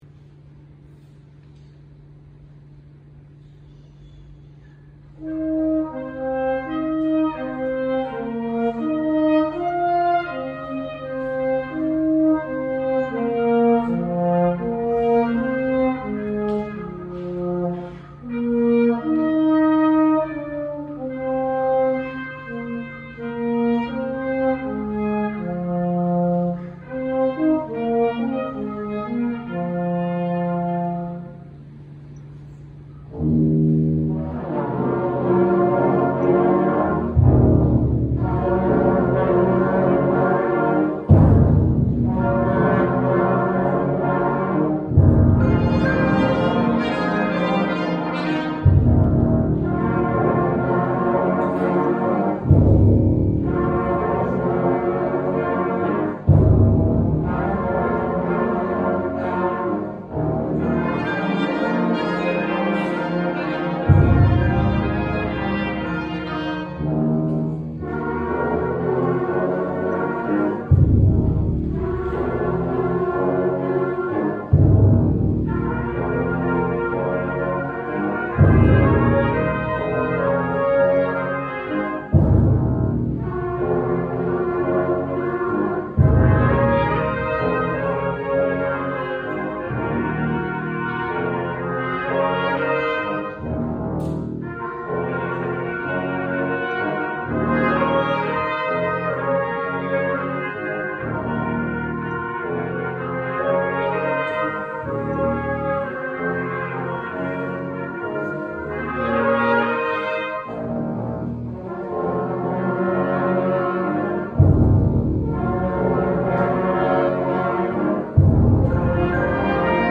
Excerpt from Spring Rounds (The Rite of Spring) | Brass Ensemble